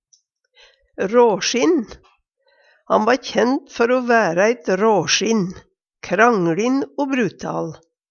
råsjinn - Numedalsmål (en-US)